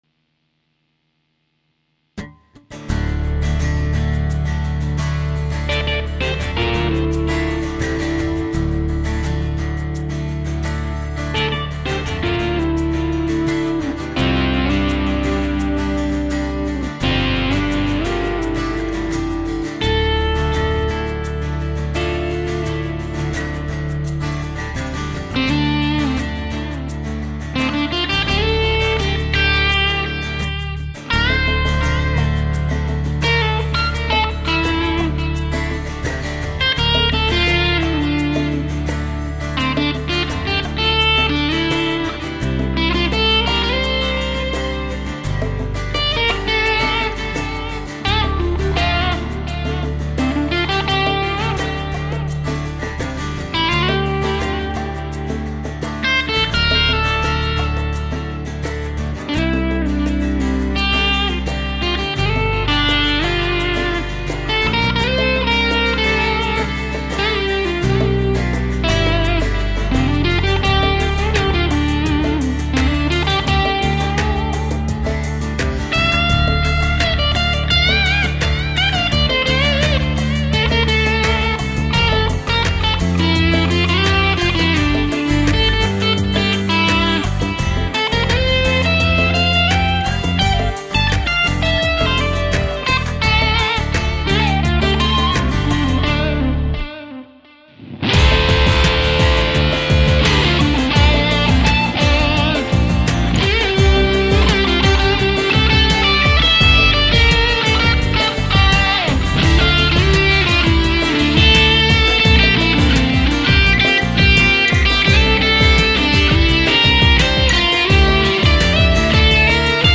Eingespielt mit der Junior, die mittlerweile einen neuen Einteiler bekommen hat (Rockinger, Alu) und dem Womanizer